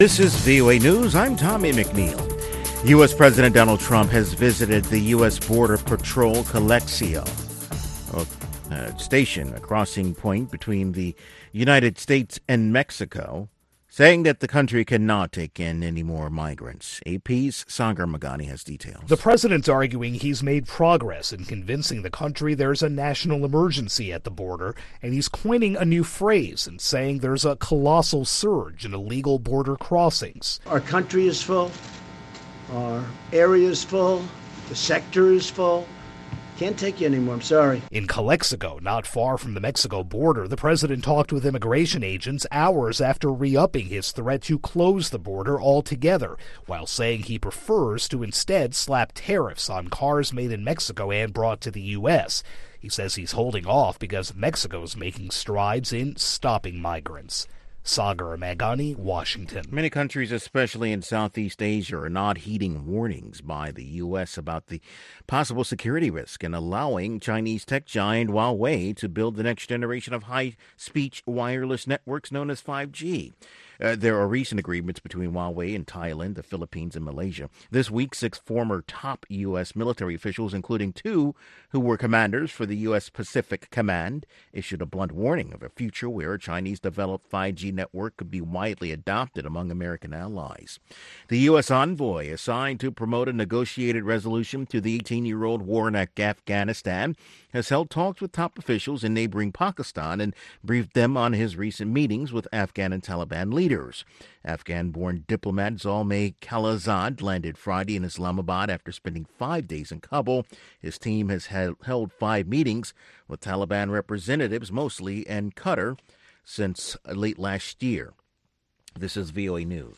This exciting pan-African playlist twists and turns through 13 countries, 3 decades, and grooves in styles from Afrobeats to Mbaqanga.